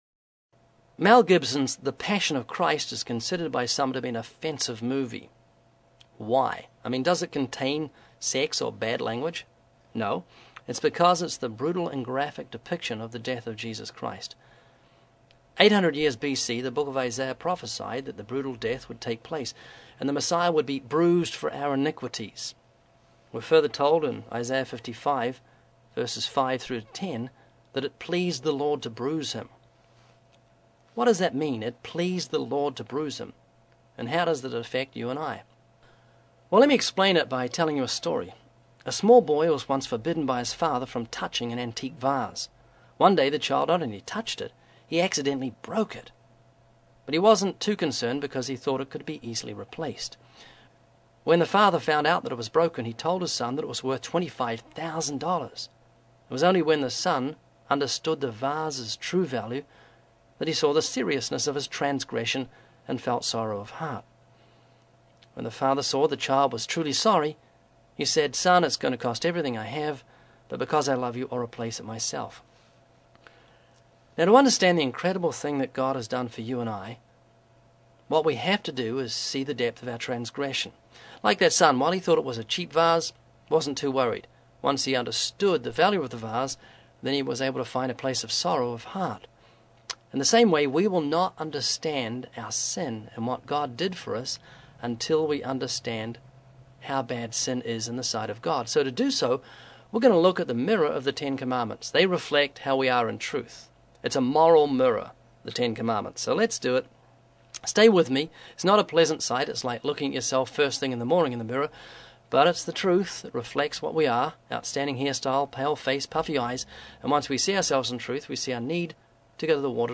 The Passion Click here to listen now This is a wonderful evangelistic "talk" perfect for giving to someone who has seen (or who is going to see) the Passion.